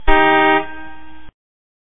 دانلود آهنگ بوق 3 از افکت صوتی حمل و نقل
دانلود صدای بوق 3 از ساعد نیوز با لینک مستقیم و کیفیت بالا
جلوه های صوتی